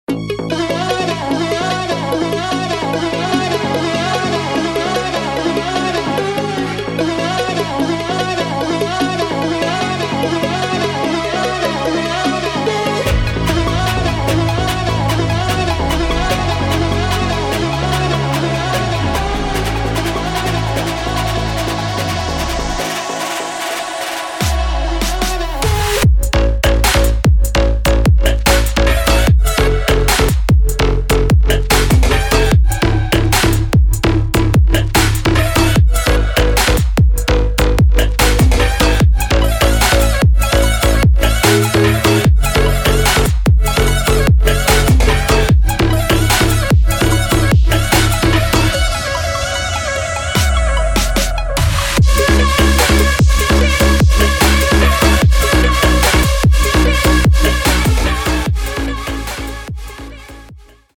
Bassline
包括惊艳的Drop、脉动的合成器和弦、异域风情的打击乐、人声等等。